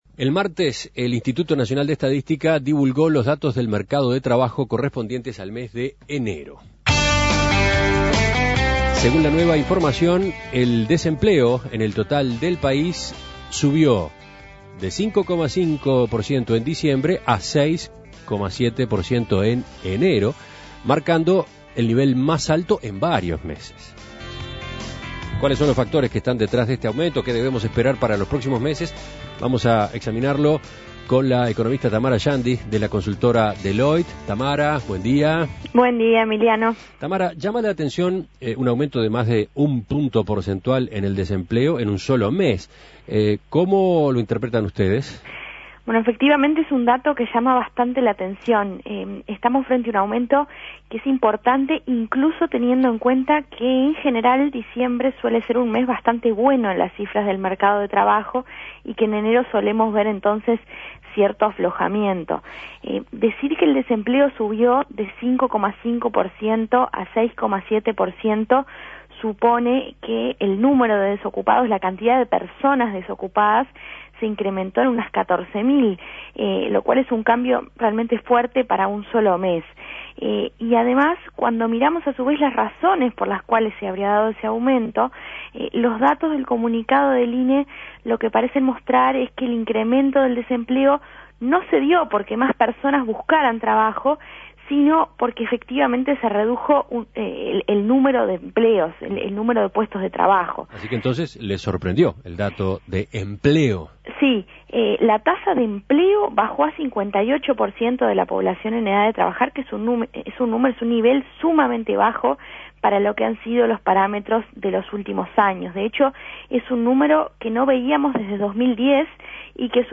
Análisis Económico Los datos del mercado de trabajo mostraron un deterioro fuerte en enero.